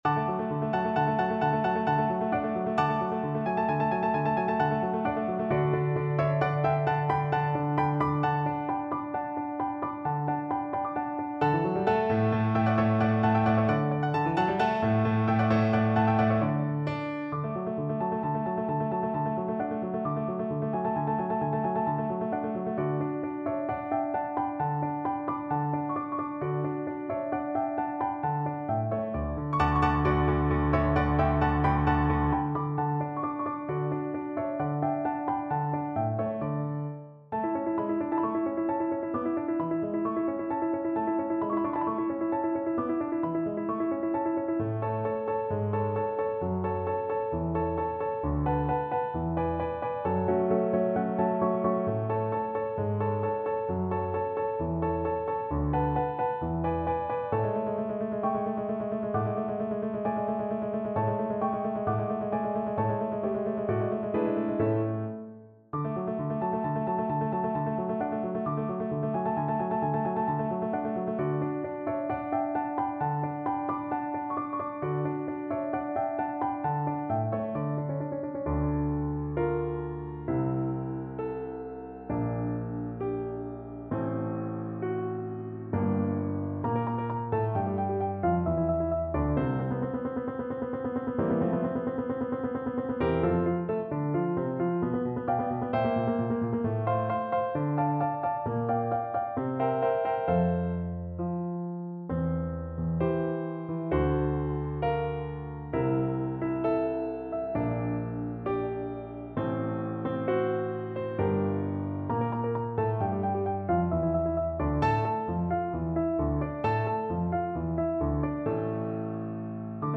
Free Sheet music for Bass Voice
2/4 (View more 2/4 Music)
D major (Sounding Pitch) (View more D major Music for Bass Voice )
Allegro vivace =132 (View more music marked Allegro)
Classical (View more Classical Bass Voice Music)